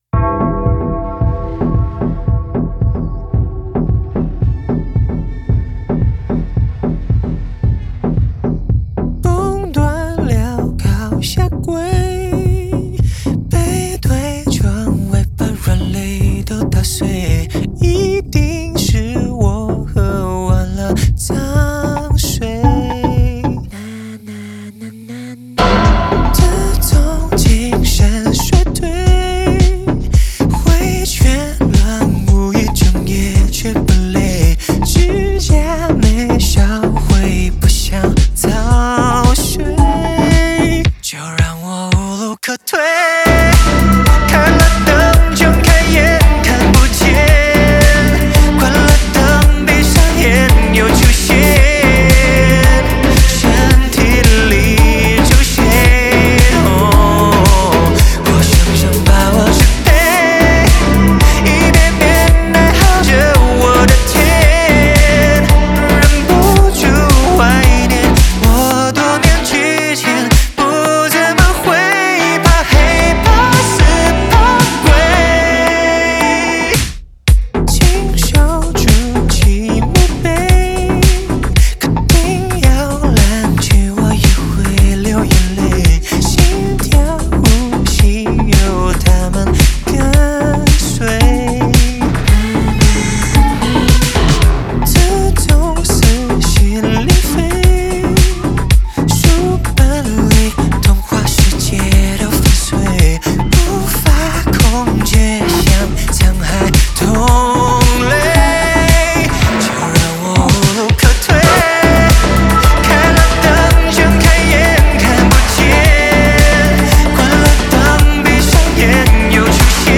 合成器 Synth
吉他 Guitar
贝斯 Bass
鼓组 Drums
和声演唱 Backing Vocal